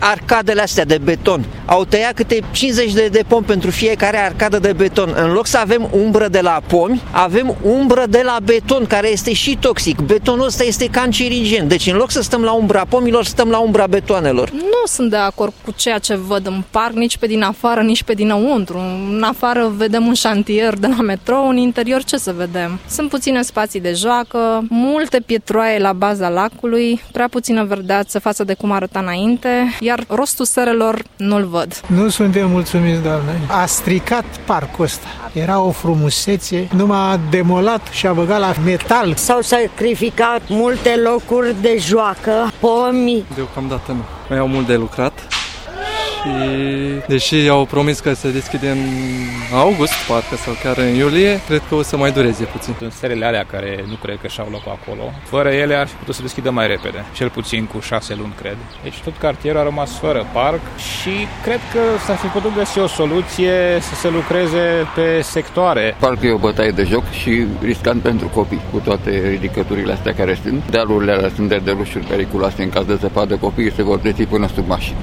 Parerea oamenilor de pe strada este unanima.
VOX-PARC-26-MAI.mp3